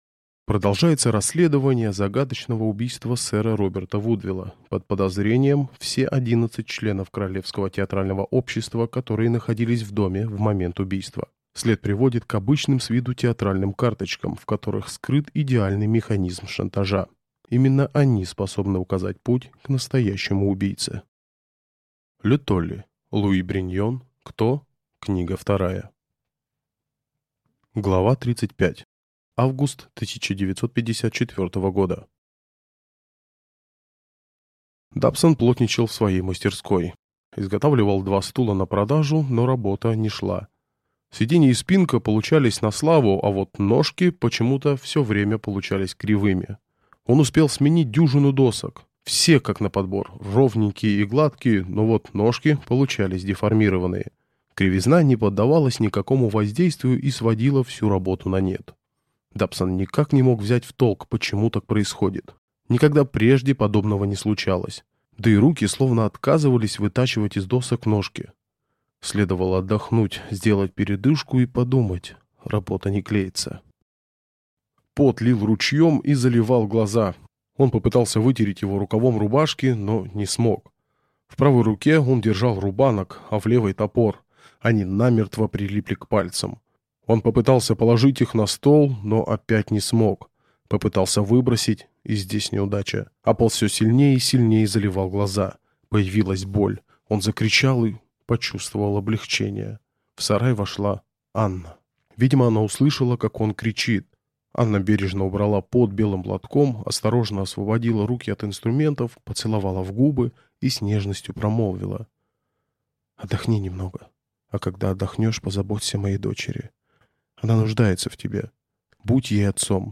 Аудиокнига К.Т.О.-2 | Библиотека аудиокниг
Прослушать и бесплатно скачать фрагмент аудиокниги